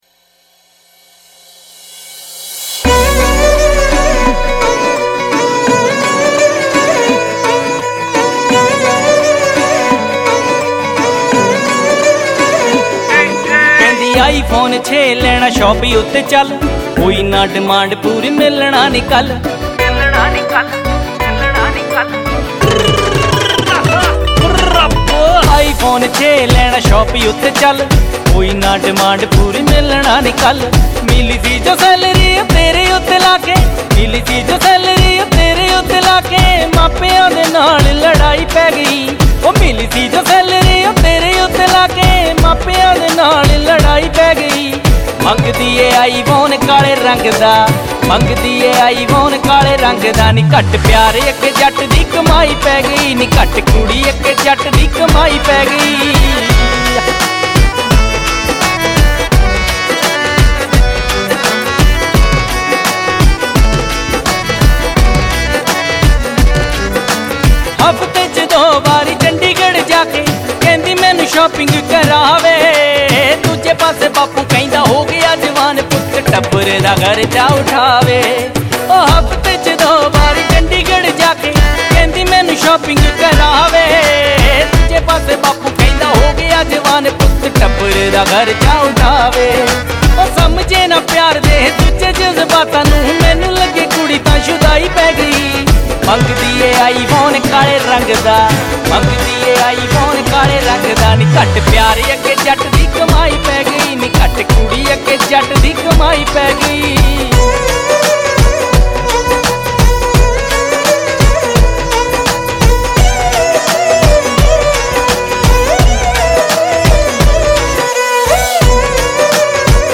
Punjabi Audio Songs, , , , , , , , ,